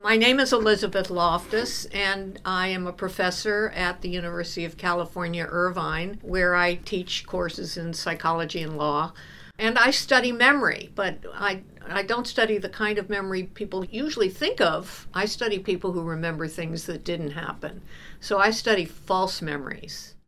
Dr. Loftus introduces herself: